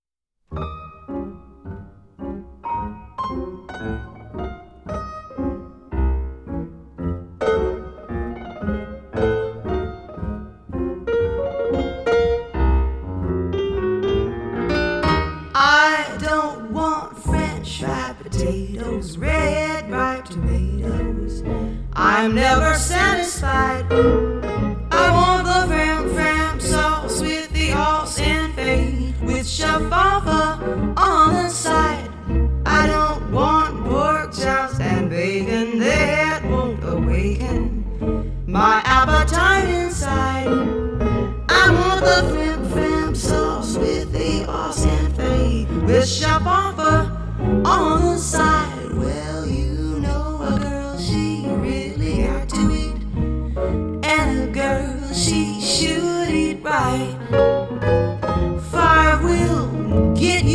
Processed (as if heard in the ancient theatre)
Both piano and voice sound different.